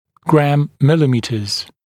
[græm-‘mɪlɪˌmiːtəz][грэм-‘милиˌми:тэз]граммы на миллиметр (ед. измерения)